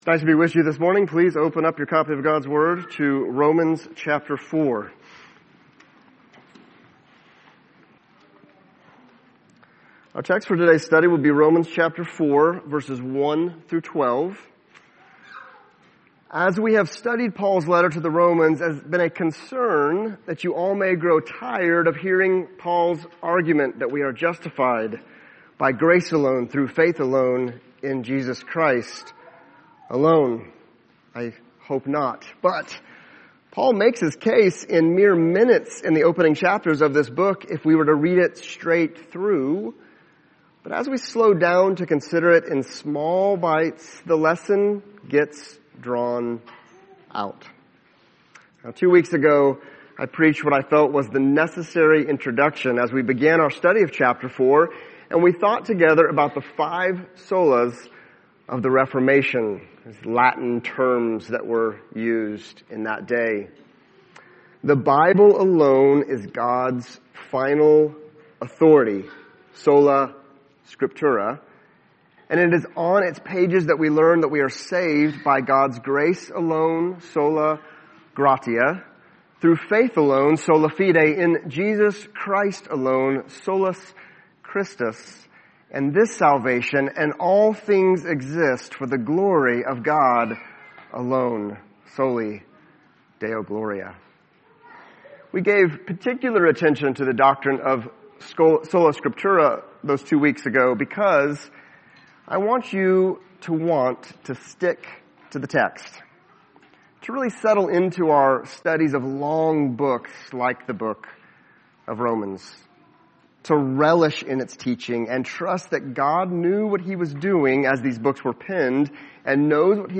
Romans 4:1-12 Service Type: Sunday Topics: Abraham , circumcision , father , justified , righteousness « John 17:20-26 Romans 4:13-25 »